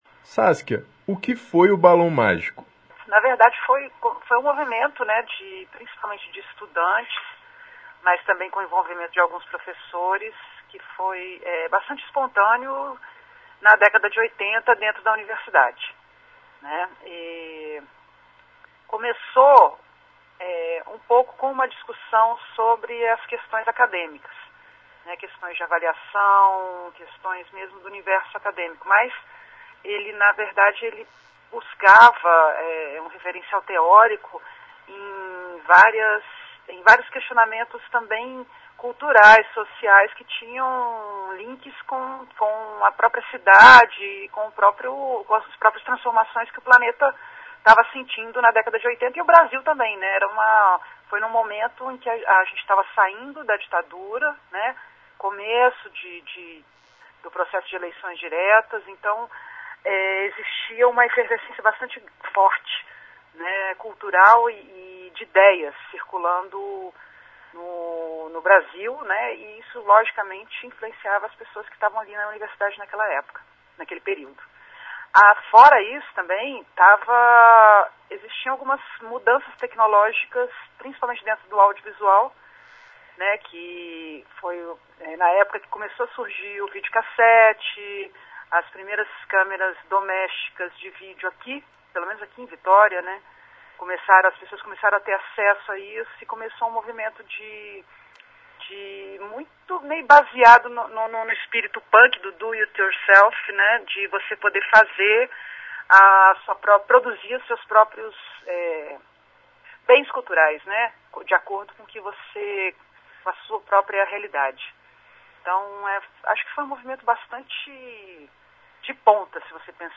Em homenagem ao aniversário da Rádio Universitária - que completa 24 anos em 2013 - ela concedeu uma entrevista para falar sobre o "Balão Mágico".